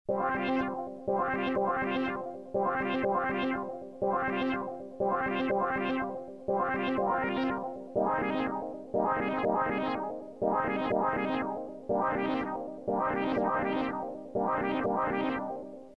16 Bit Digital Synthesizer
demo synth: 1 2 3 4